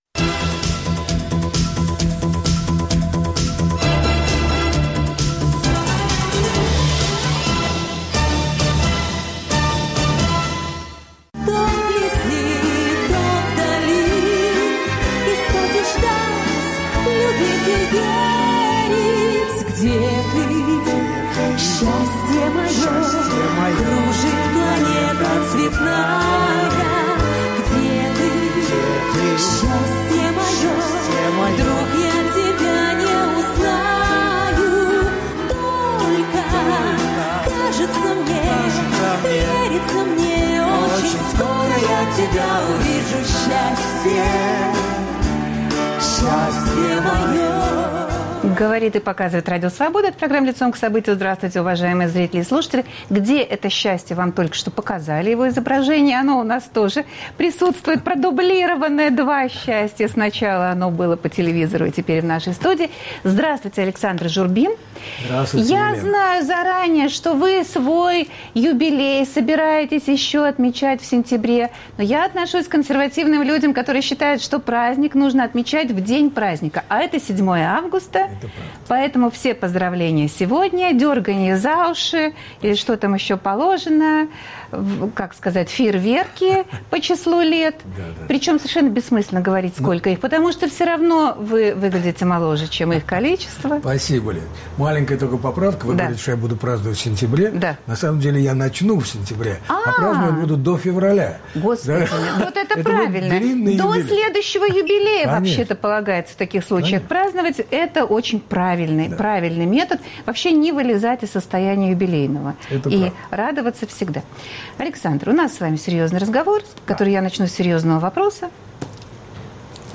Свои 75 маэстро празднует в студии РС.